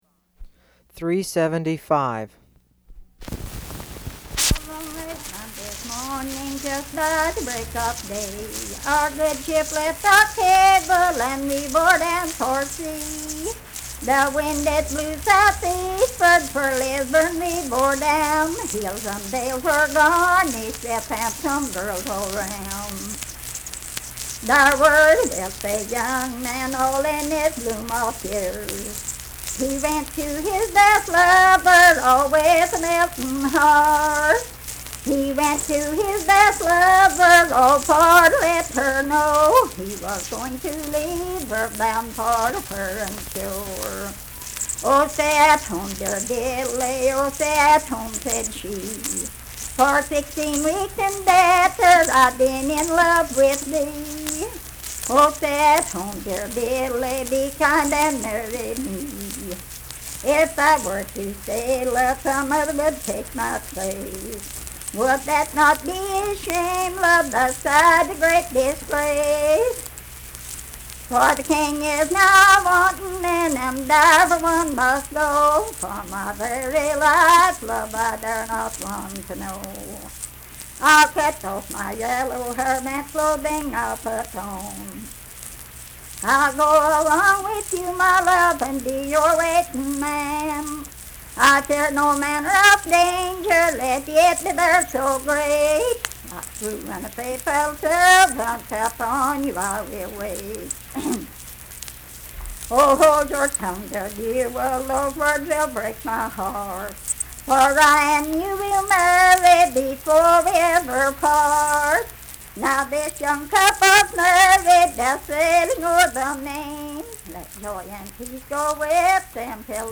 Unaccompanied vocal music
Verse-refrain 9d(4).
Voice (sung)